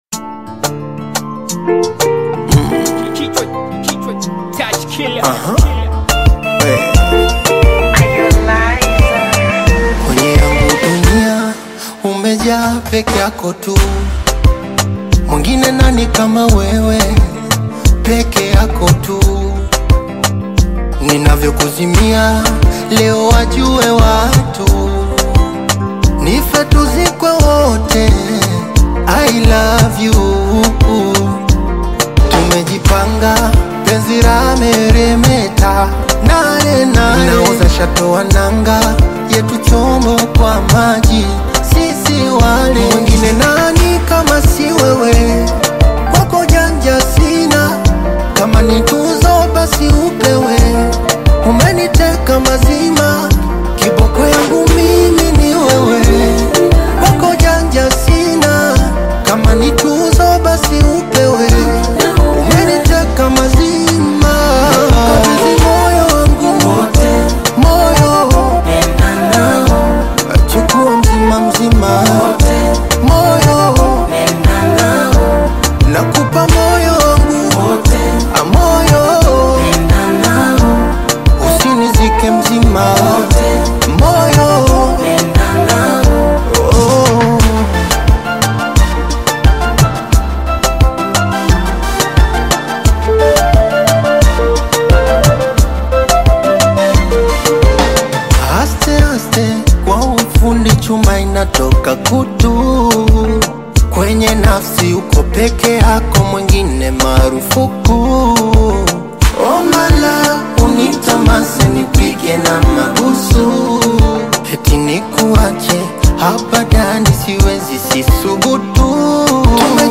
Bongo Flava